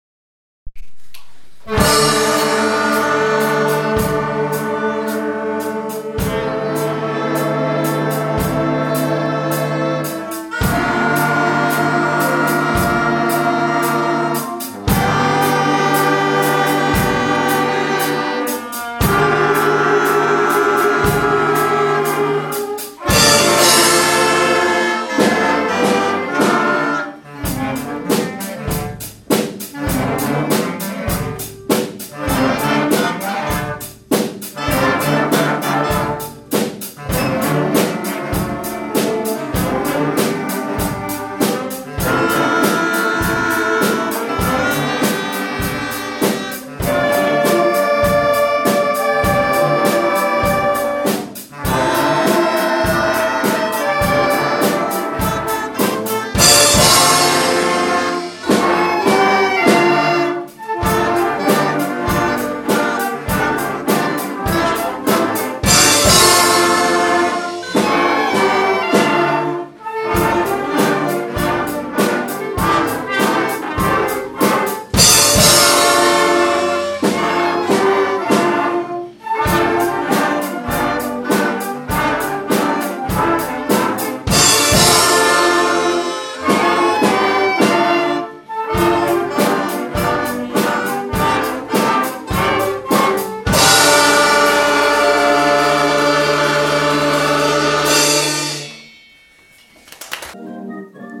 Thriller - primary wind day